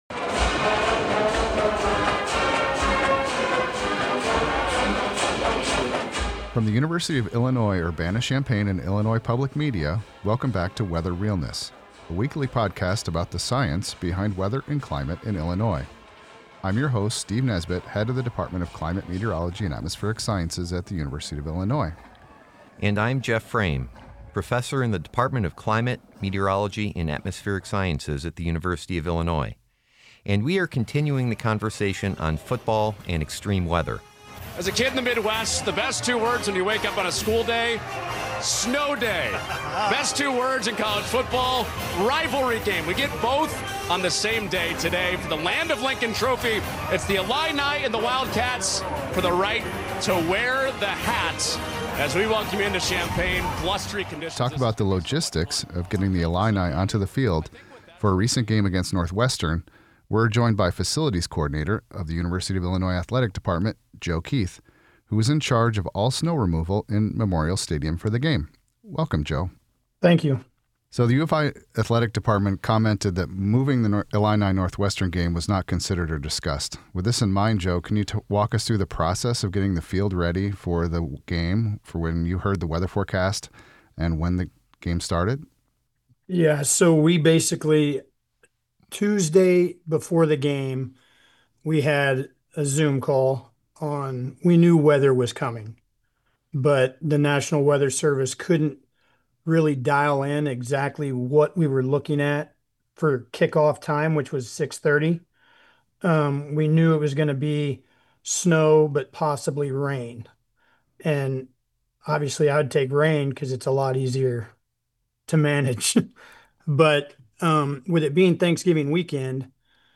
We talked with the University of Illinois Urbana Champaign about how it prepared Gies Memorial Stadium for a snowy game in late November 2025.